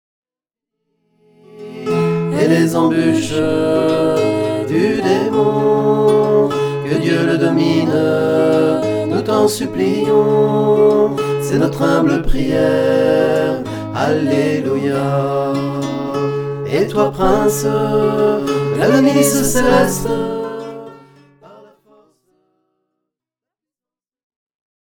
Chaque mystère est accompagné de chants pour la louange
Format :MP3 256Kbps Stéréo